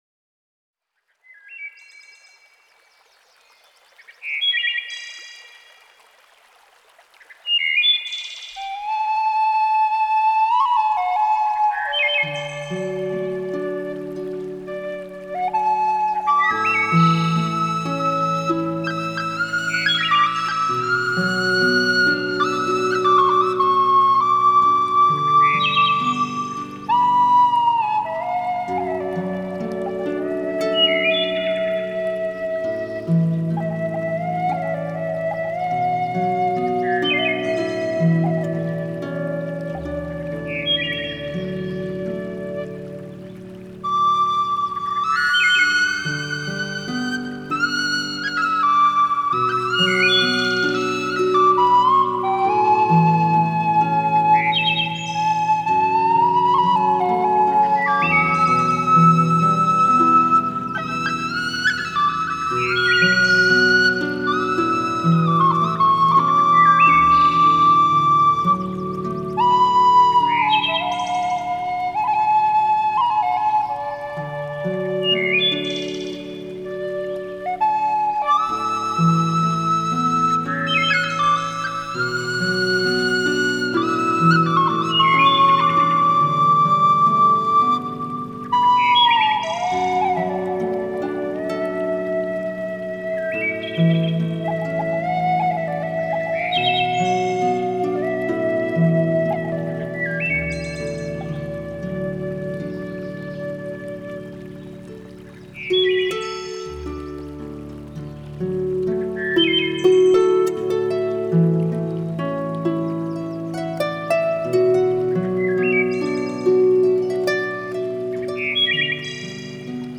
冥想音乐2.mp3
冥想音乐2